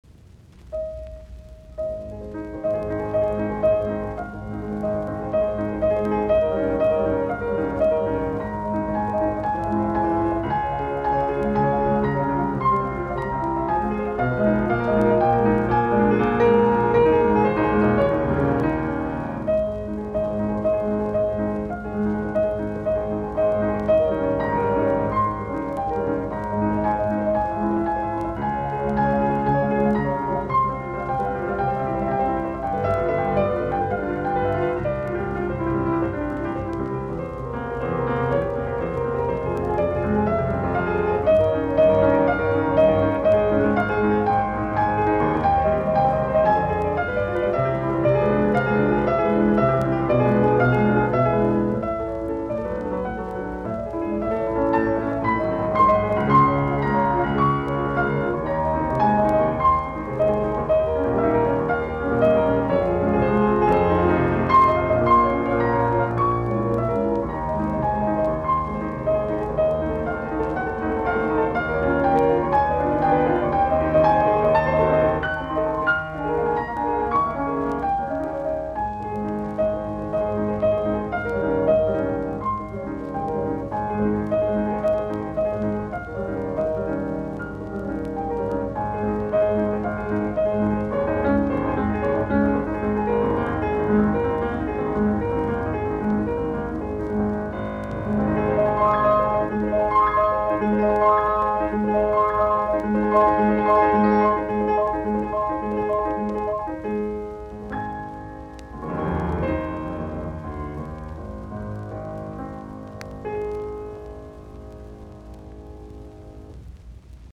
Etydit, piano, op25